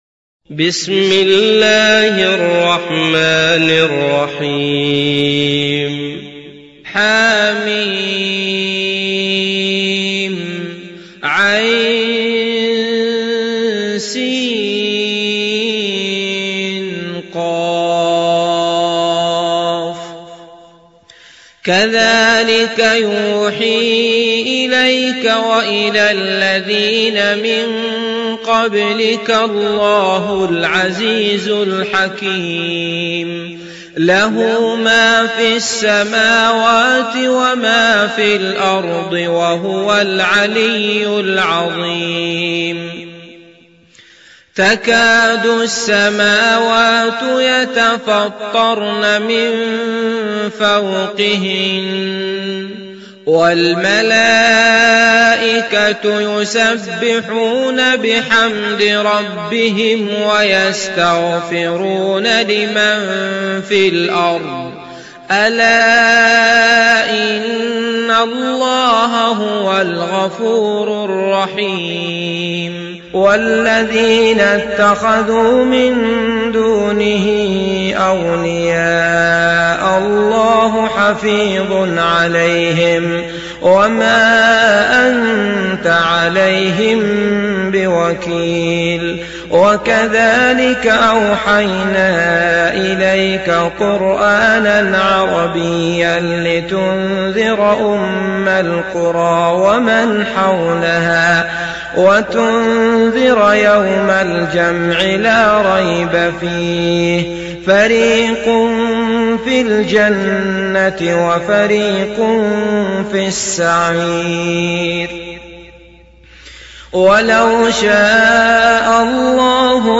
Şura Suresi İndir mp3 Abdullah Al Matrood Riwayat Hafs an Asim, Kurani indirin ve mp3 tam doğrudan bağlantılar dinle